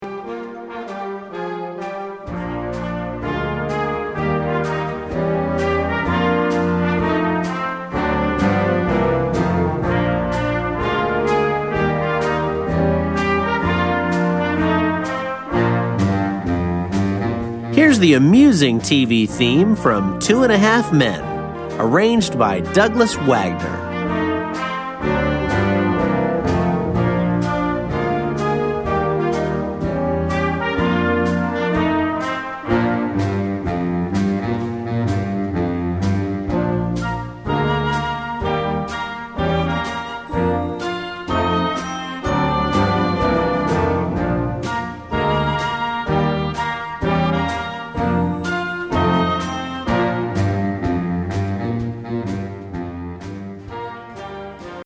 Filmmusik für Jugendblasorchester
Besetzung: Blasorchester